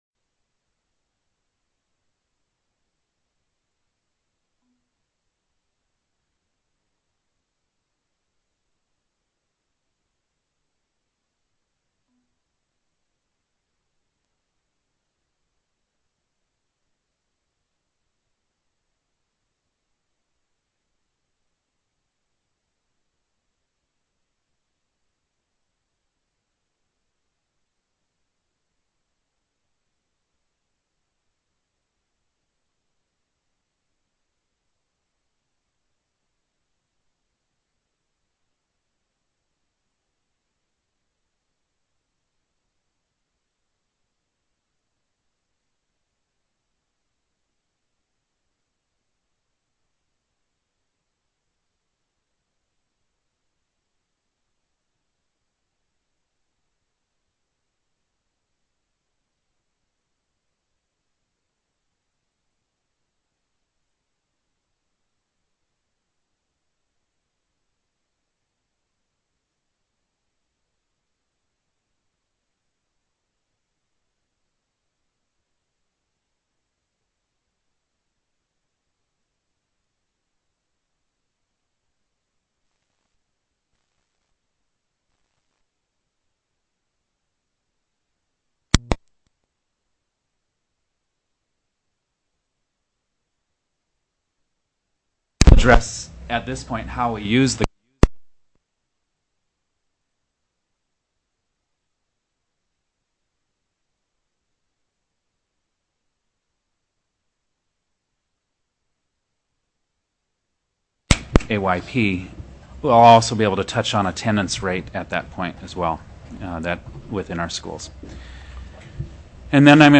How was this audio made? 01/26/2007 08:00 AM Senate SPECIAL COMMITTEE ON EDUCATION